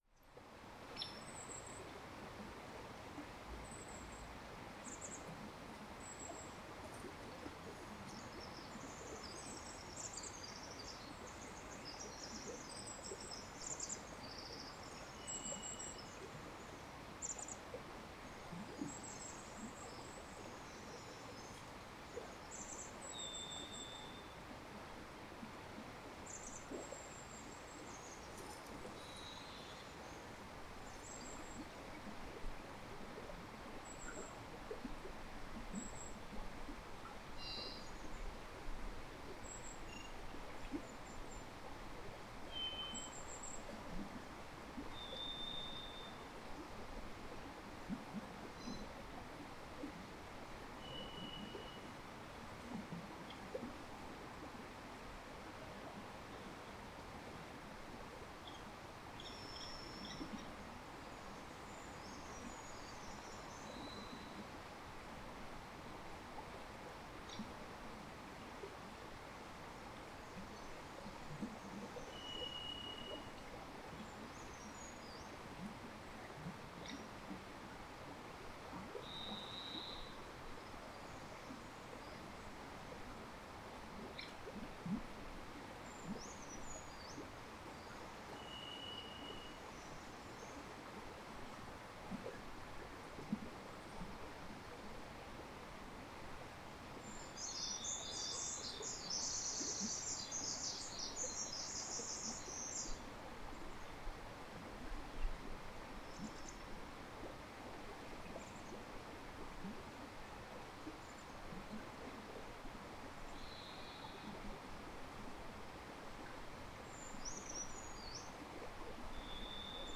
Recordings from the trail through the beautiful old growth forest at Fillongley Provincial Park on Denman Island with the bird- and creek-sounds in early spring 2022.
5. Bird sounds (Buddha Tree) – Kinglet, Wren, Chickadee, Thrush, Hairy Woodpecker (creek)
There were sounds captured with the recorder that were not heard or were difficult to hear with the human ear – including the soft woodpecker tapping sounds and the wing-beats, which sound almost like a bird bathing.